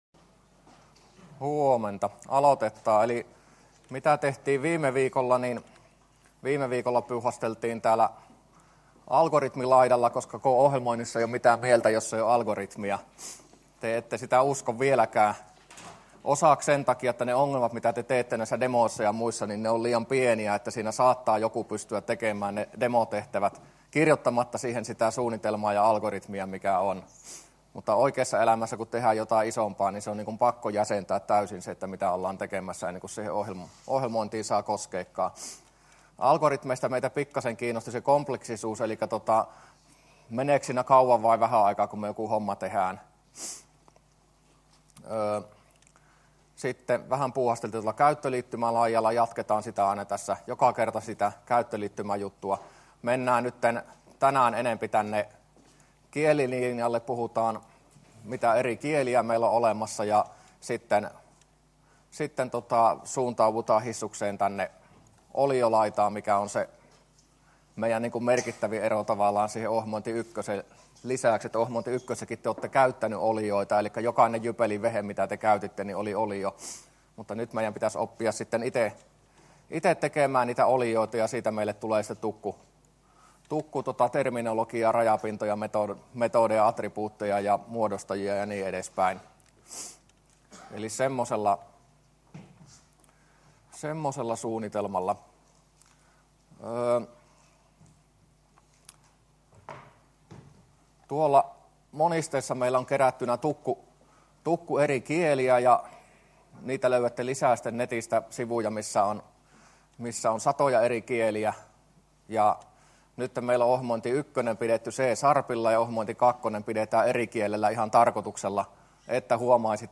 luento05a